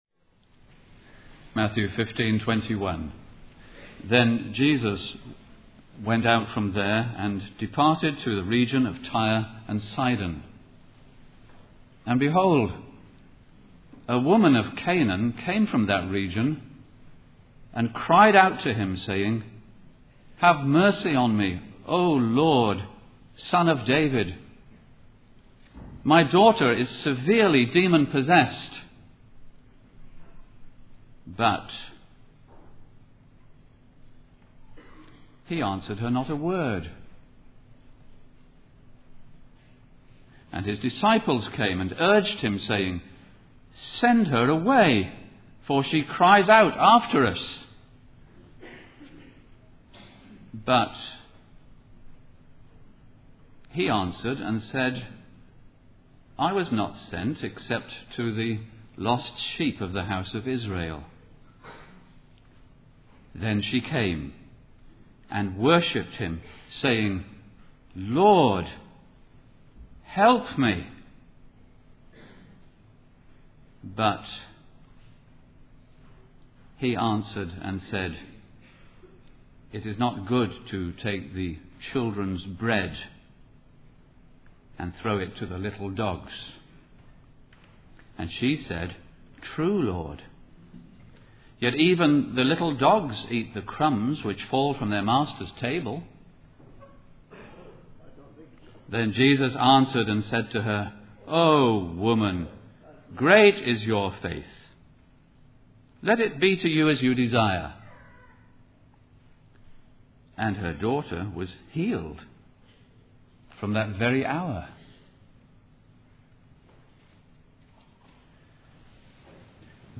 In this sermon, the speaker shares a personal story about a beggar who comes to his family's door asking for a sandwich.